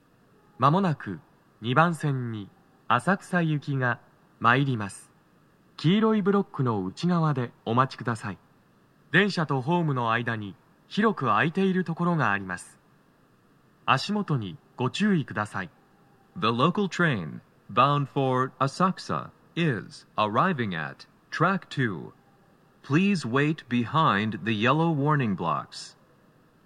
スピーカー種類 TOA天井型
🎵接近放送
鳴動は、やや遅めです。
男声